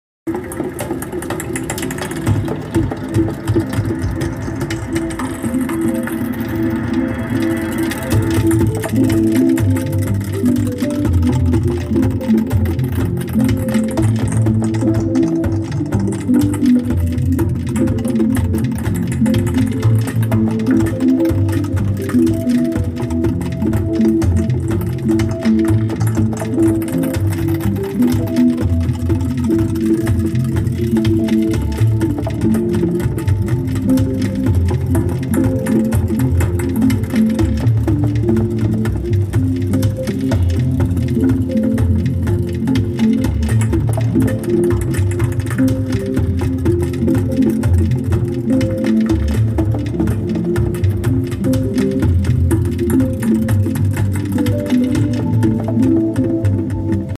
Stock audio after TUNING. sound effects free download